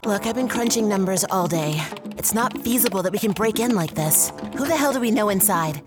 standard us | character